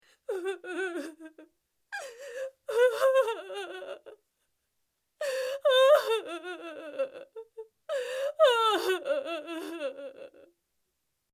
LLANTO DE MUJER DOLIDA
Tonos EFECTO DE SONIDO DE AMBIENTE de LLANTO DE MUJER DOLIDA
llanto_de_mujer_dolida.mp3